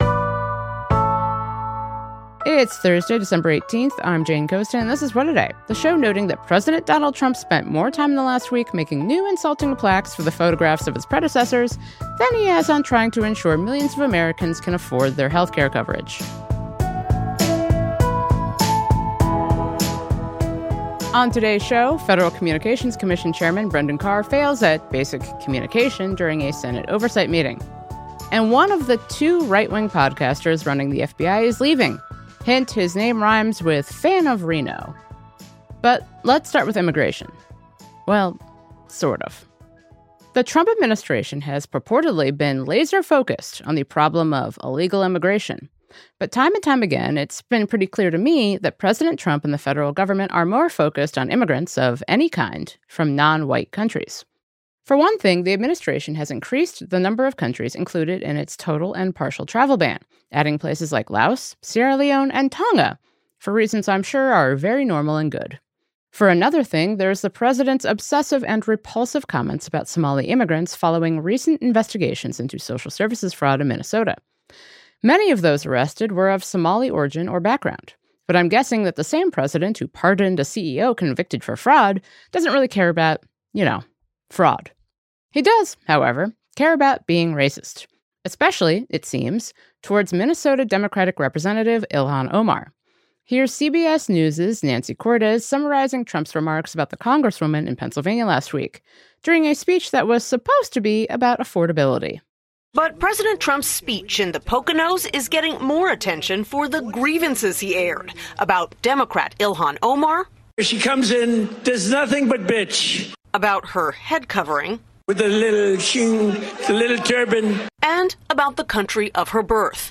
We spoke to Representative Omar about the President’s attacks against her and the Somali American community, her work in Congress, and whether healthcare really can be saved before the new year.